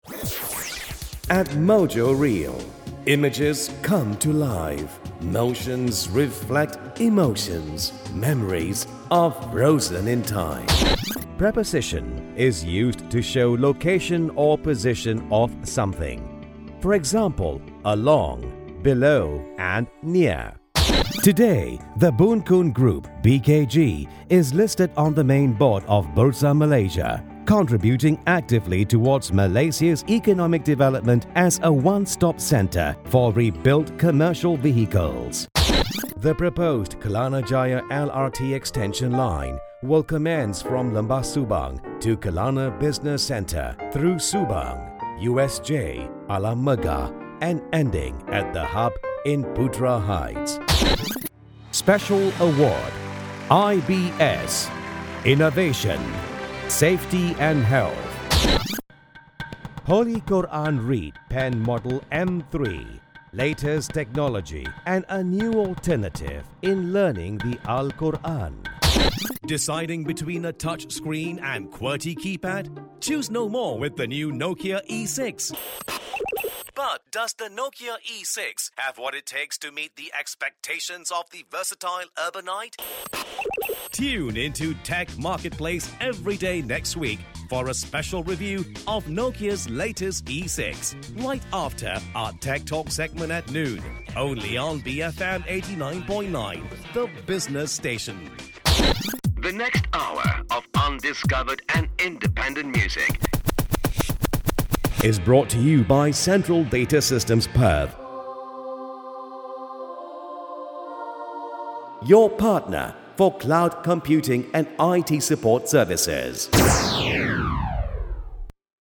malay, malay vo talent, malaysia voice talent bahasa malaysia voice talent
Kein Dialekt
Sprechprobe: Industrie (Muttersprache):